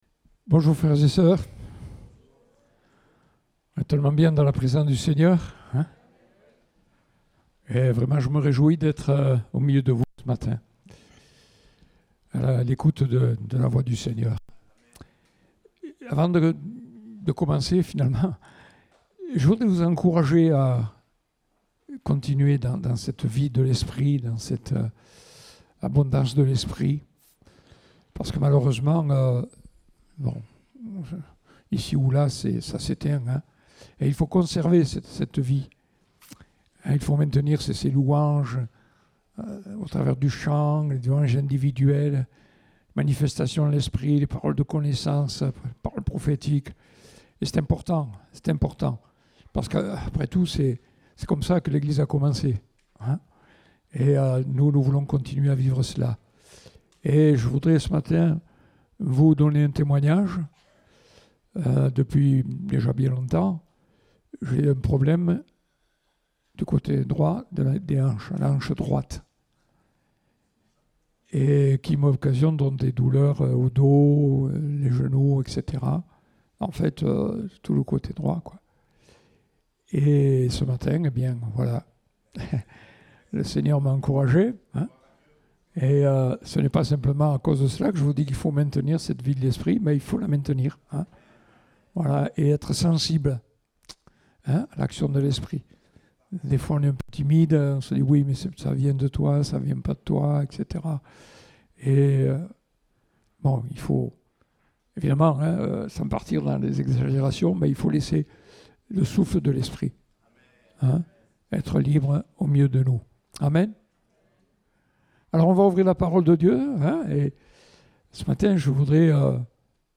Date : 26 juin 2022 (Culte Dominical)